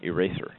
eraser.mp3